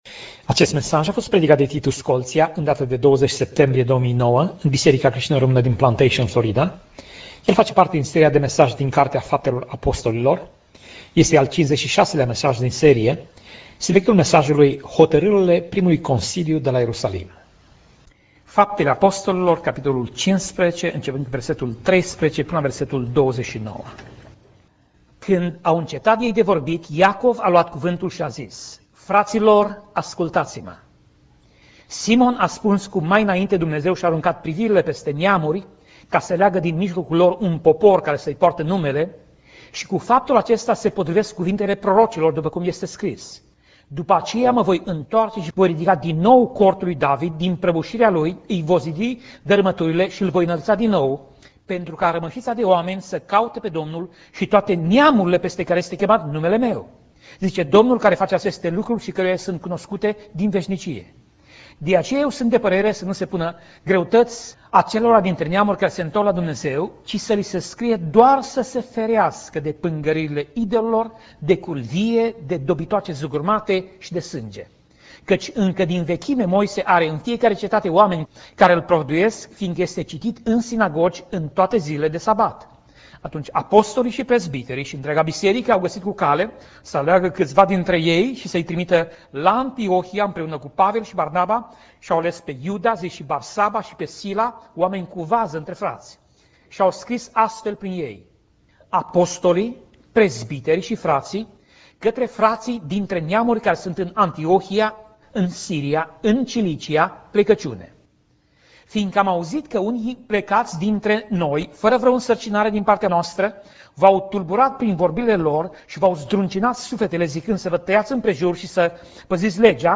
Pasaj Biblie: Faptele Apostolilor 15:12 - Faptele Apostolilor 15:29 Tip Mesaj: Predica